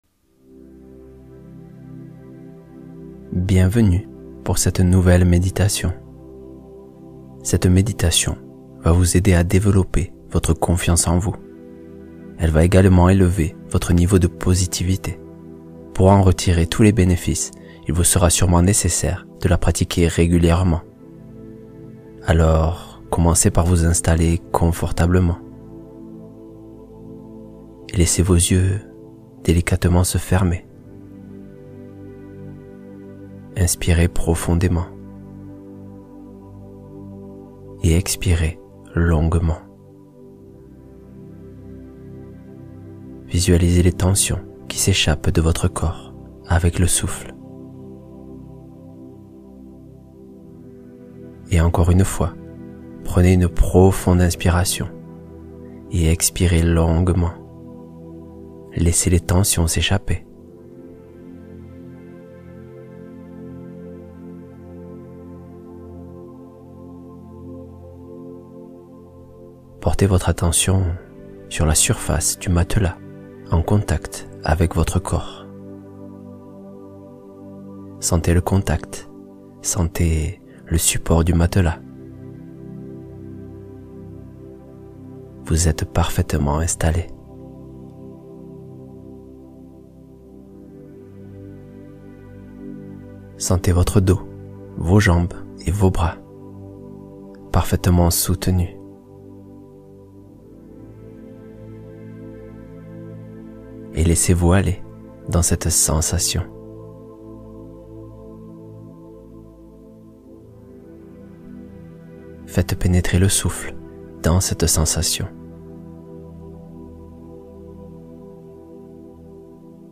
Se retrouver — Méditation pour renforcer estime et stabilité